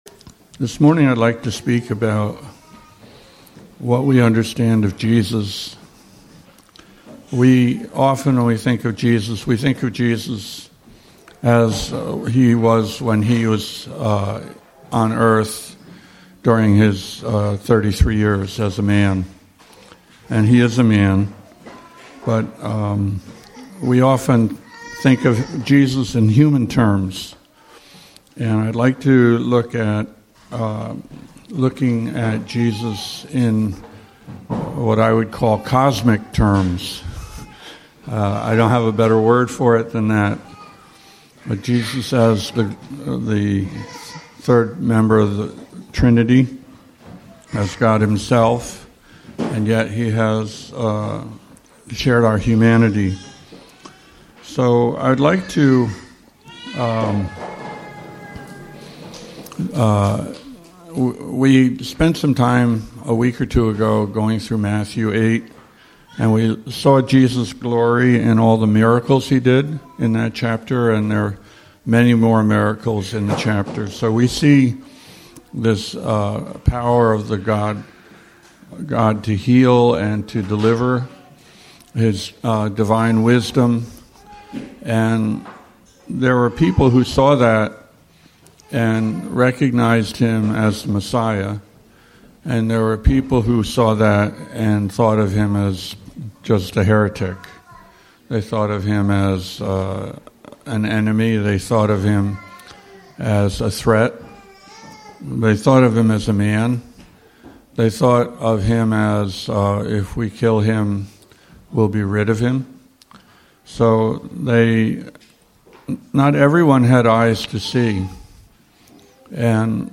He emphasizes the importance of spiritual perception, humility, and reverence, drawing from biblical accounts of Jesus' transfiguration, post-resurrection appearances, and future judgment. The sermon challenges believers to live with eternal perspective, honoring God and